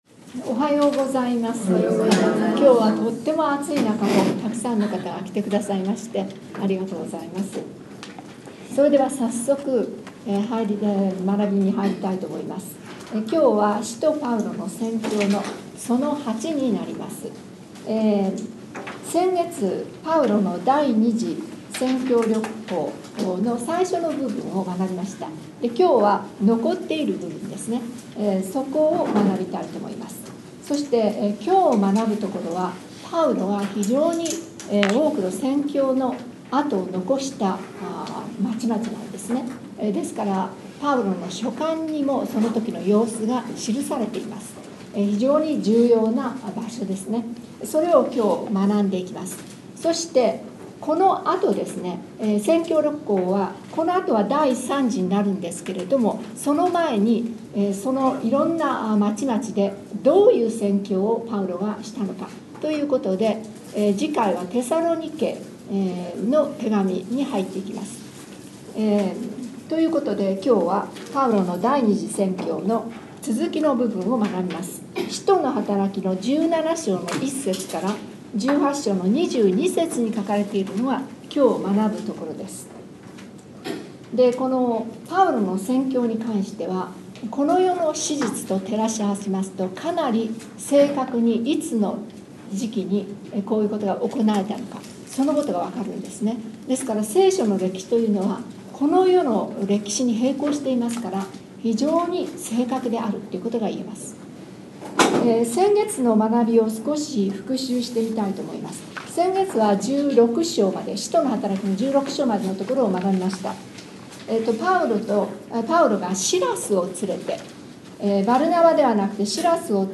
7月10日に第40回ヨシェルの会が開催されました。
厳暑の最中、久し振りに参加してくださった方もおられ、情熱あふれる講義を共に味わう良い時間となりました。